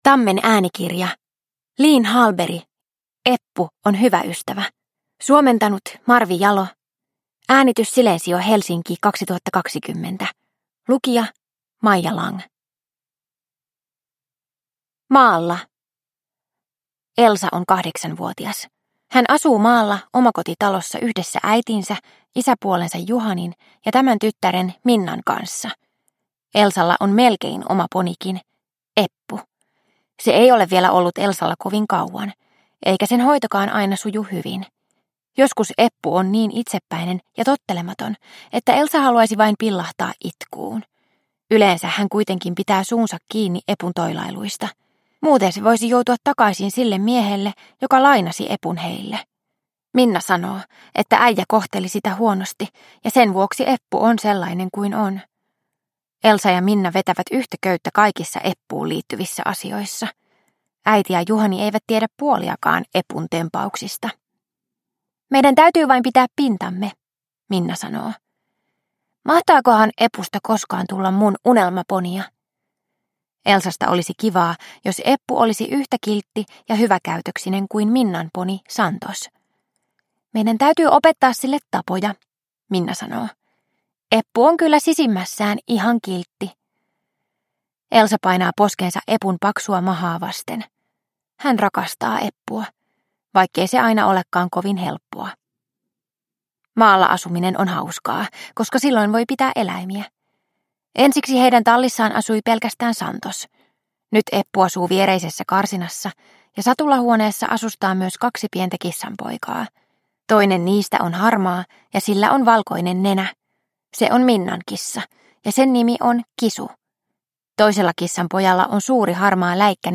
Eppu on hyvä ystävä – Ljudbok – Laddas ner